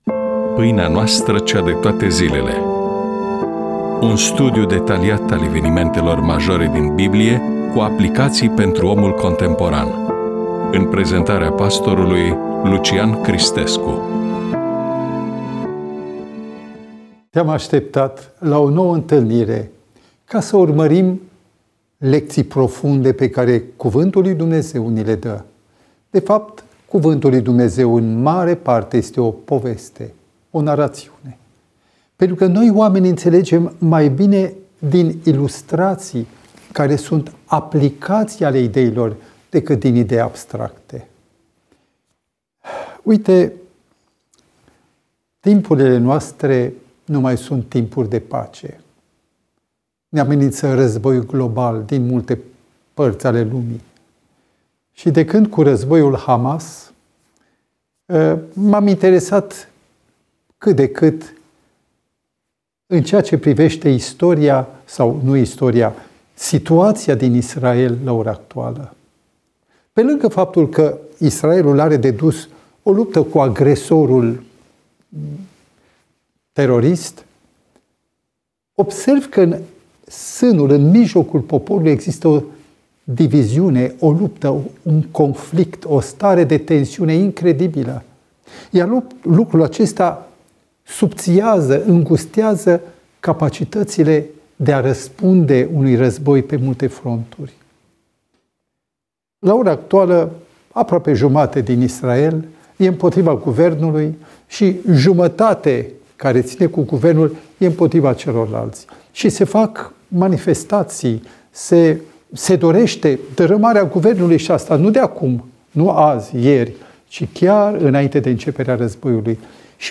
EMISIUNEA: Predică DATA INREGISTRARII: 06.03.2026 VIZUALIZARI: 16